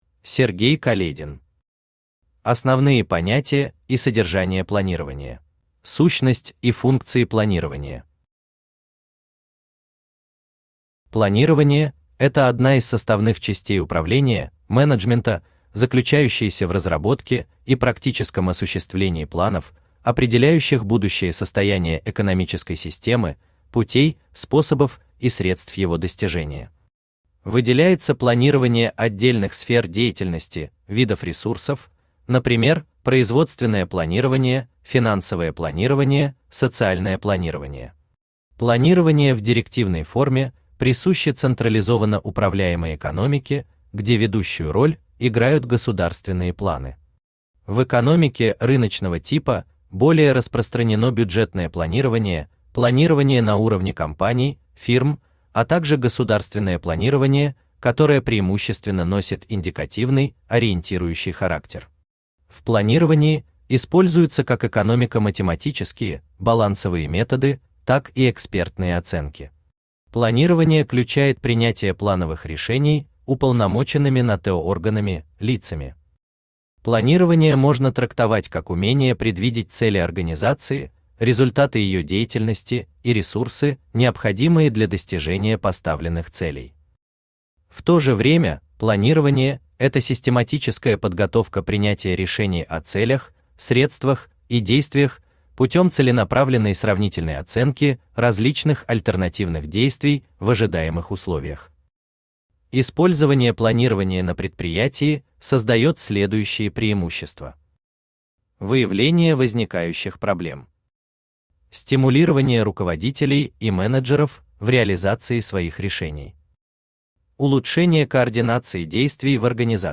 Аудиокнига Основные понятия и содержание планирования | Библиотека аудиокниг
Aудиокнига Основные понятия и содержание планирования Автор Сергей Каледин Читает аудиокнигу Авточтец ЛитРес.